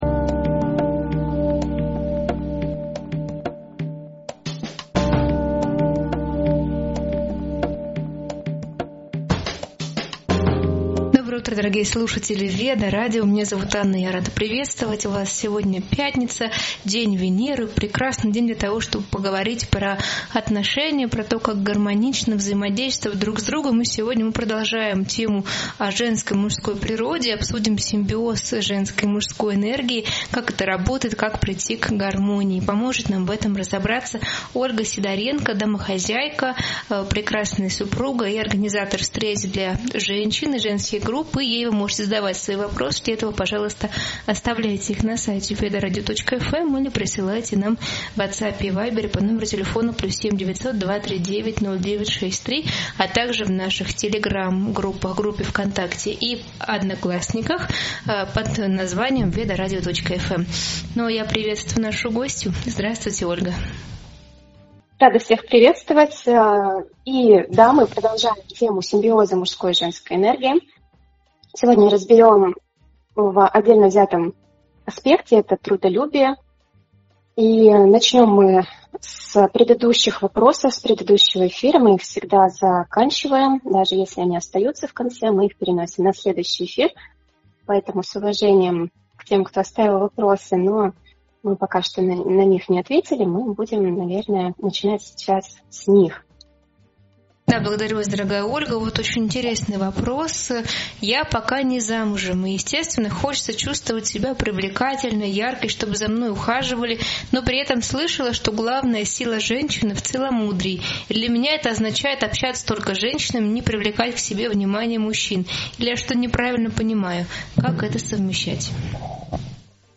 Эфир посвящён гармоничным отношениям и балансу в семейной жизни. Обсуждаются различия мужской и женской природы, распределение обязанностей, труд домохозяек и финансовые аспекты брака. Поднимаются темы общения, уважения и формирования привычек, влияющих на атмосферу в семье.